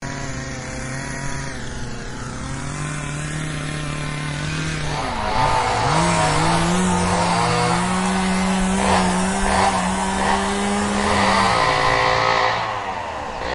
Dual Chain Saws
SFX
yt_aNJYN-Hb9_Q_dual_chain_saws.mp3